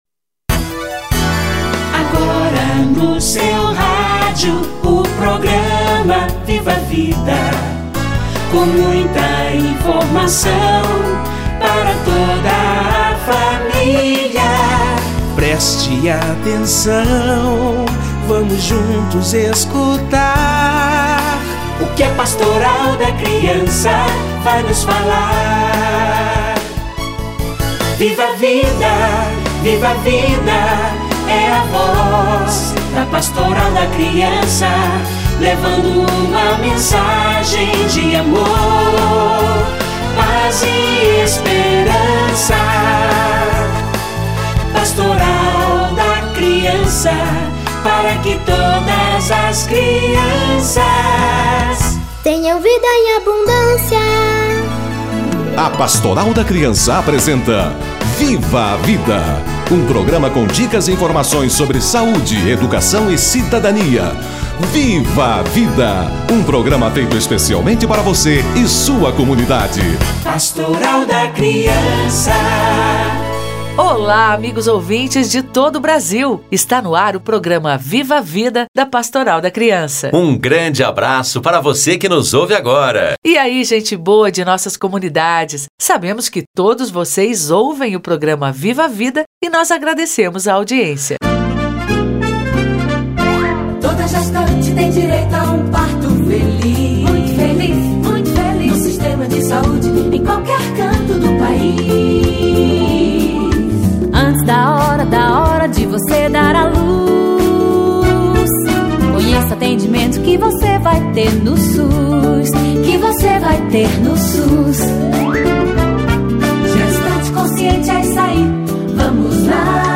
Consequências da cesariana - Entrevista